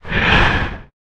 exhale.ogg